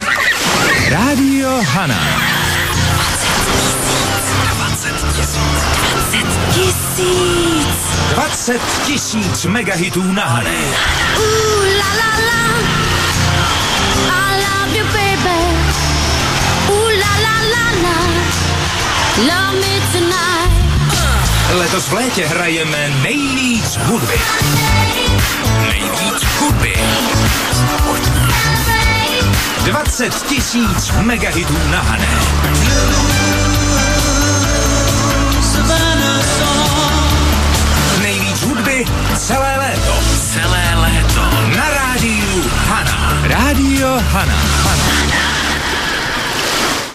CLAIM 20 TISÍC MEGAHITŮ LÉTA NA HANÉ